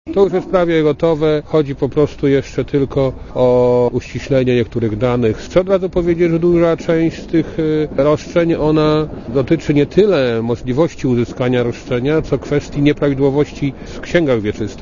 * Mówi Ryszard Kalisz*